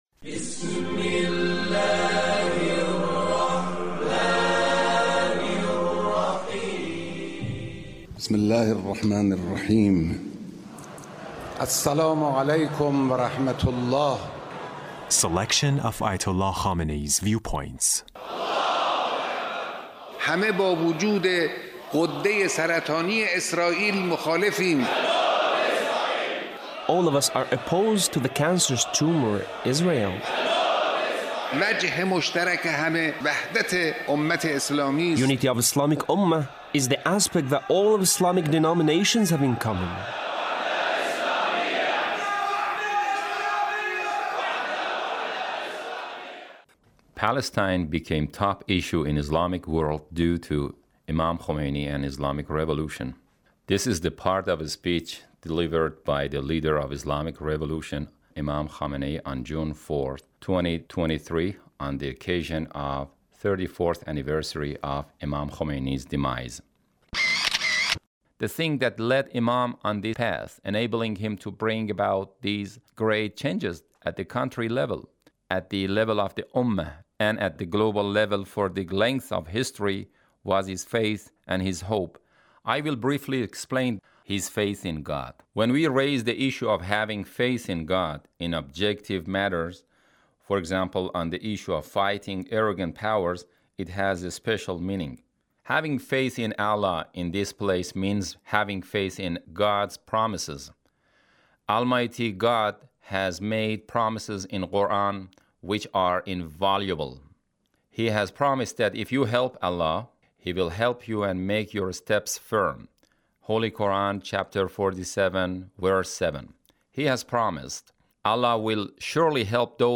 Leader's Speech on the occasion of the 34th anniversary of Imam Khomeini’s demise. 2023